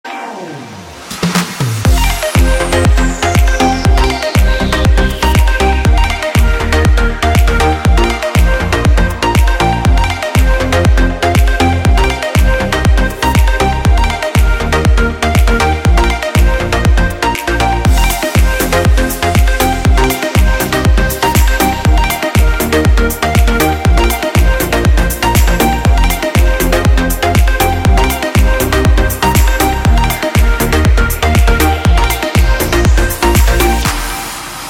• Качество: 128, Stereo
громкие
без слов
Dance Pop
Заводная музыка